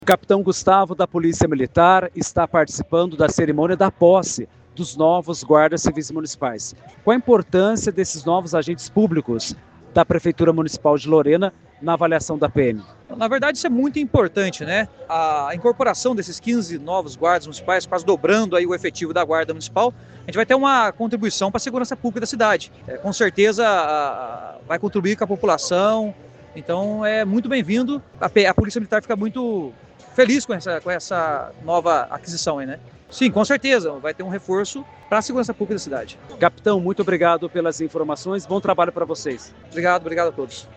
Entrevistas (áudio):